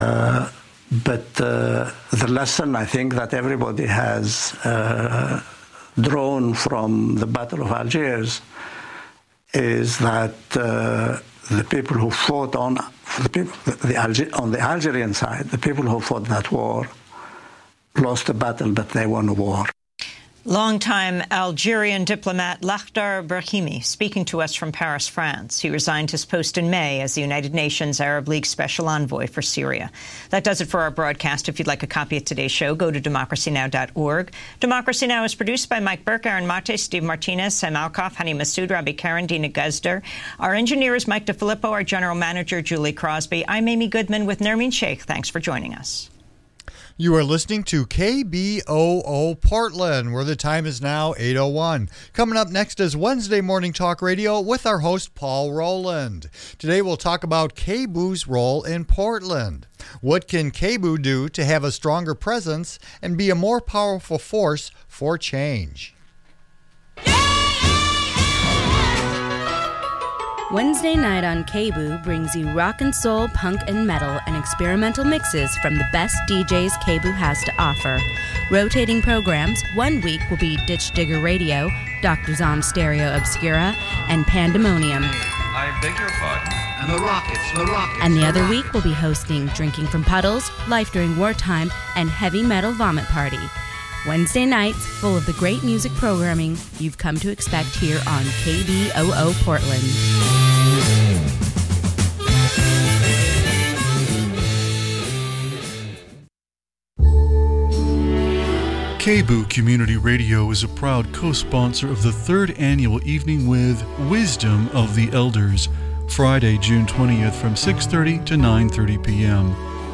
Wednesday Talk Radio